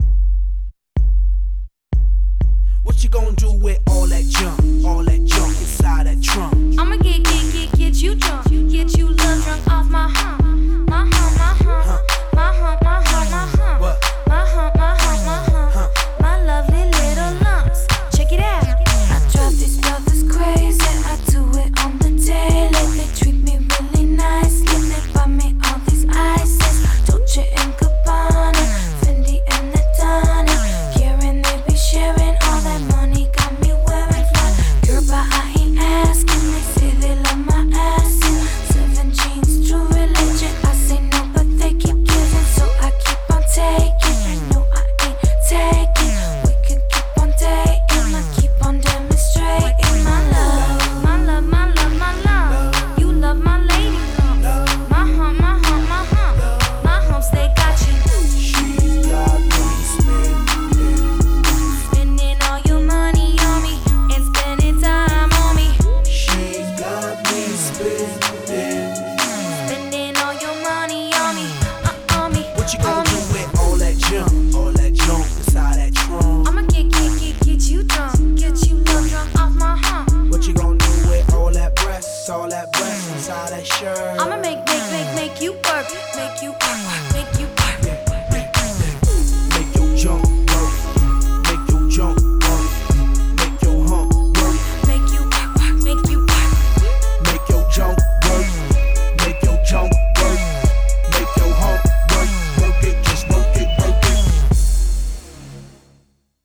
BPM124
Genre: Pop Rap